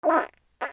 おなら
/ J｜フォーリー(布ずれ・動作) / J-25 ｜おなら・大便